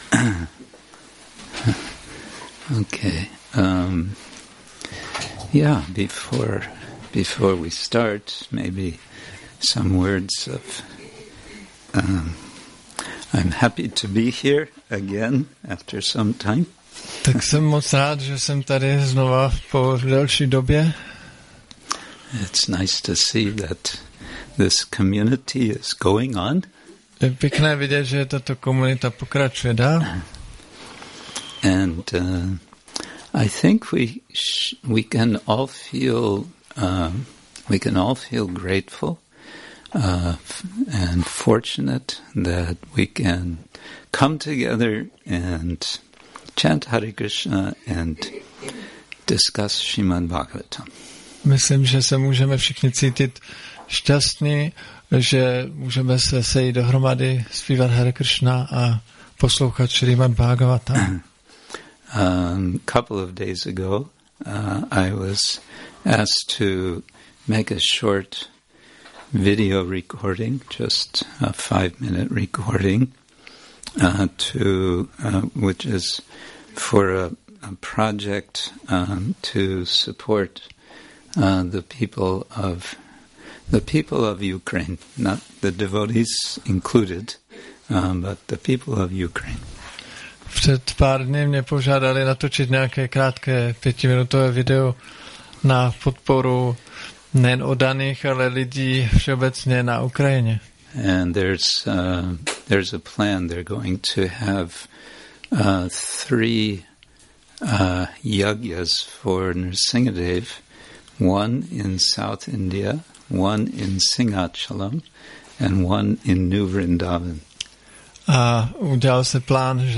Přednáška SB-10.13.58 – Šrí Šrí Nitái Navadvípačandra mandir